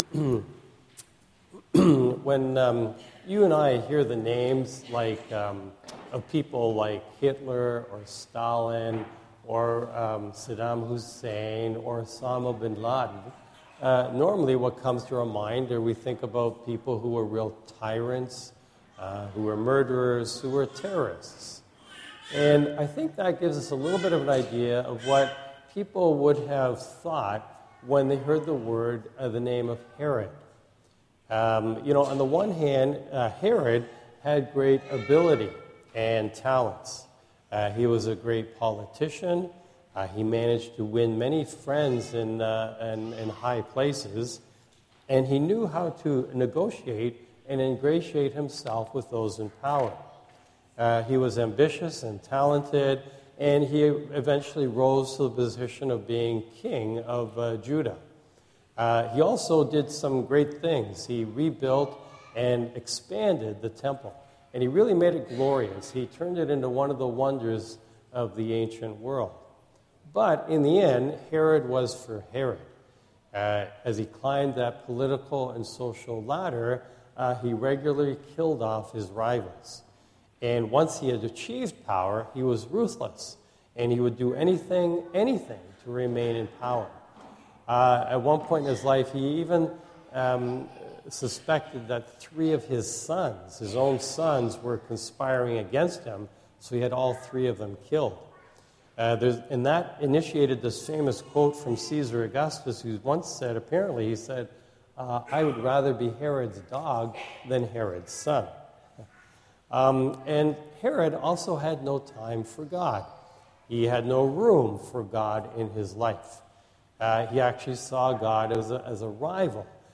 Homily
recorded at Holy Family Parish